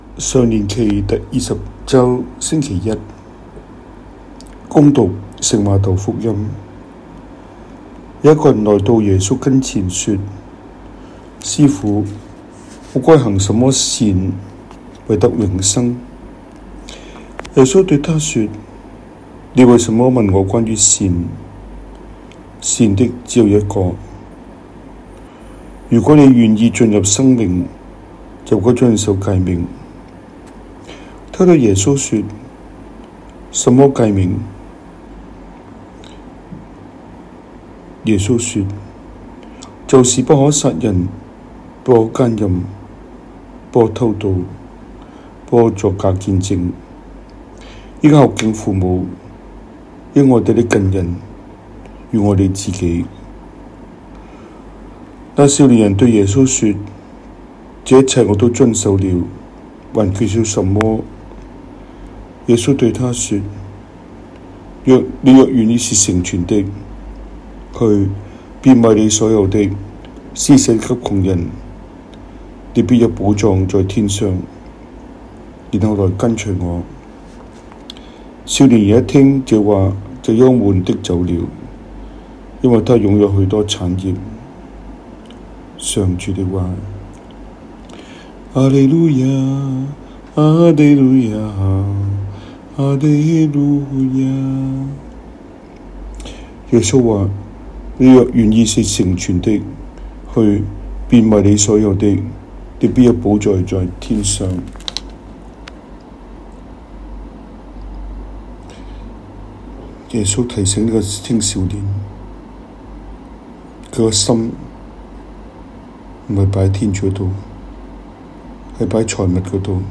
中文講道, 英文講道